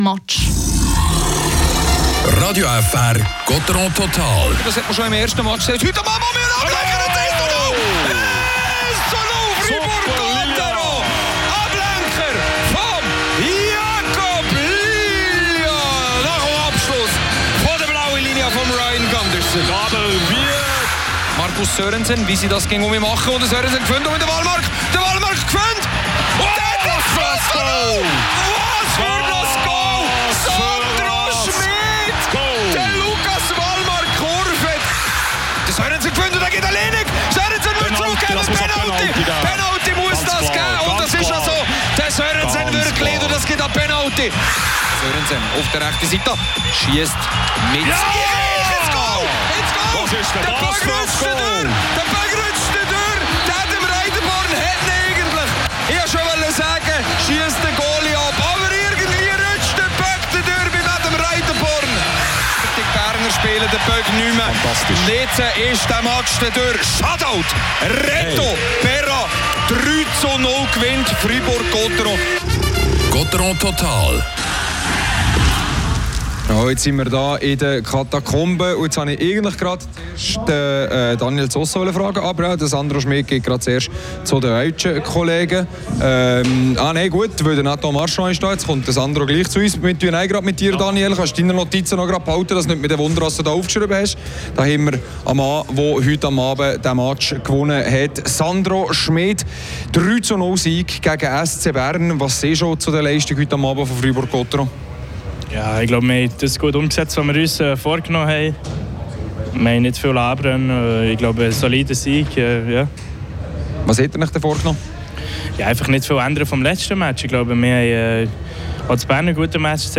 Spielanalyse
Interviews